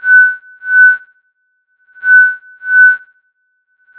The sound file is the recieved signal as heard by the observer.